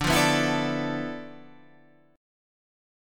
Listen to Dm6add9 strummed